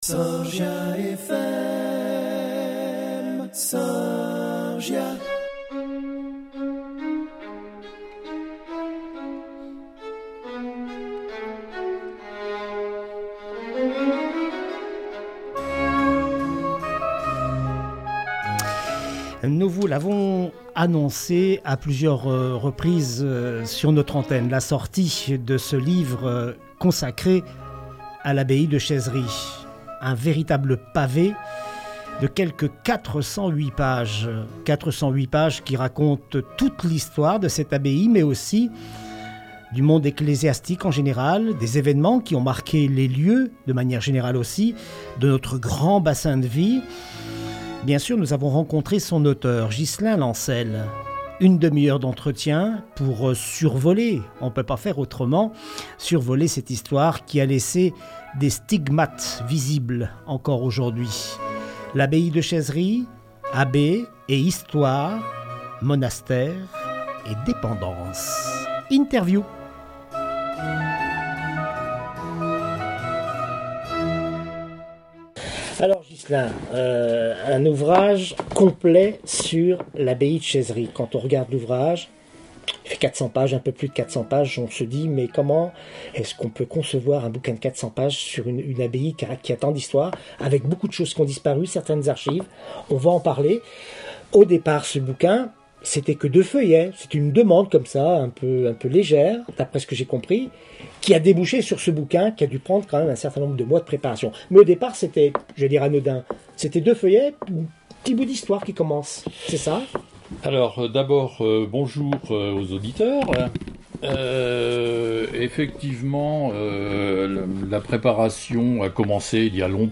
Interview par SORGIA FM
Ecouter l'interview (25 min, qualité réduite) Voir les années précédentes depuis 2008 Mises à jour permanentes au cours de l'année 2020.